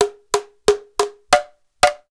PERC 29.AI.wav